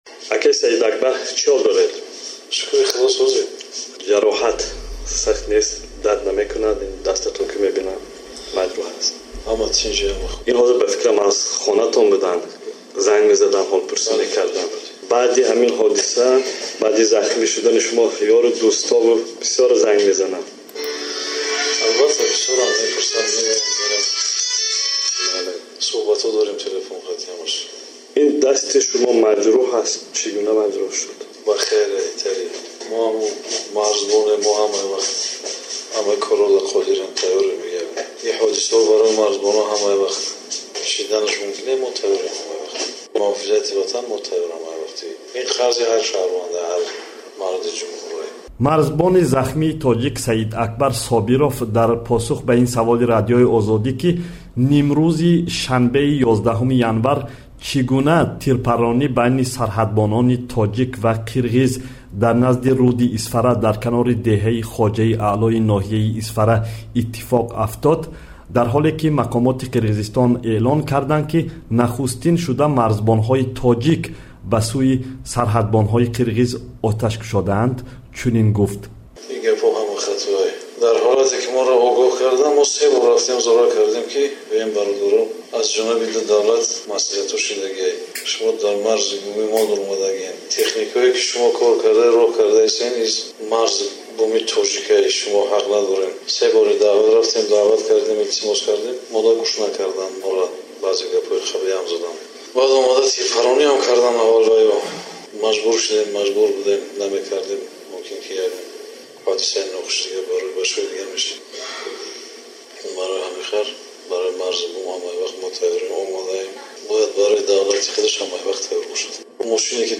Гуфтугӯ